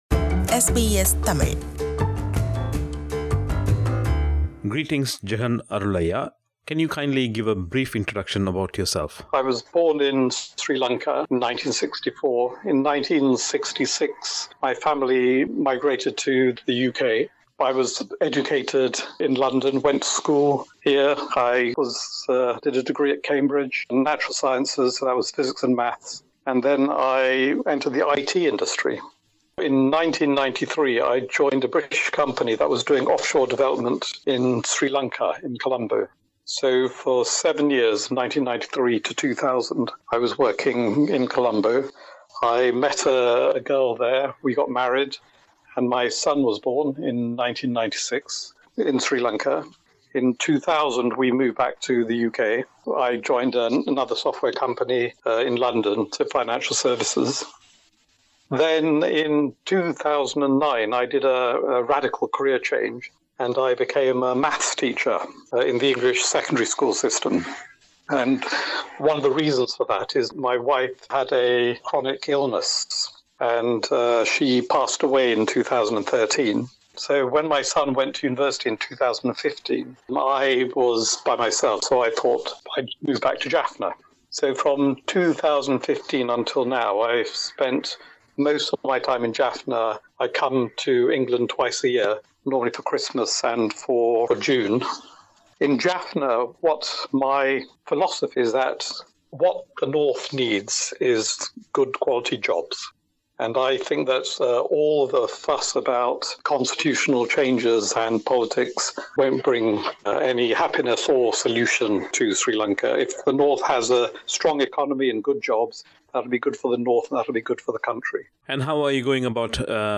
diaspora_tamils_invest_back_home_web_english.mp3